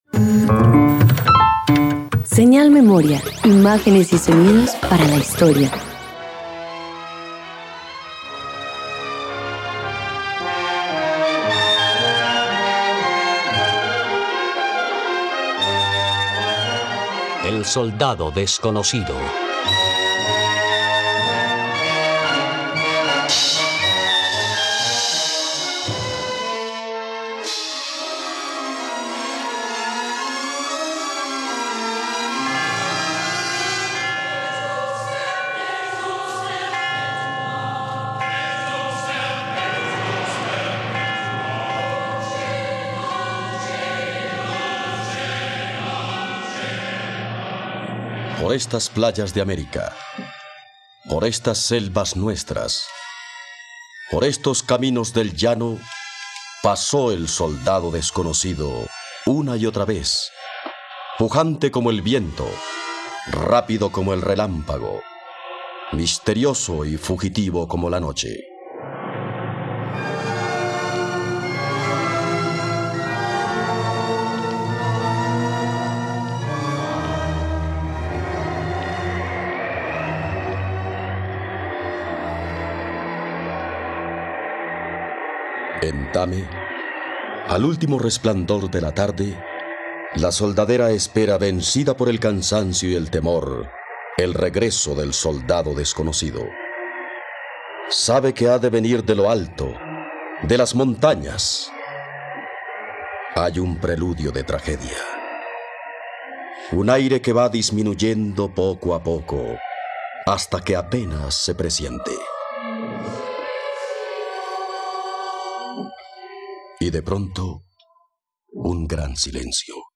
El soldado desconocido - Radioteatro dominical | RTVCPlay